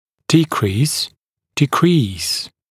[‘diːkriːs] гл. [dɪ’kriːs][‘ди:кри:с] гл.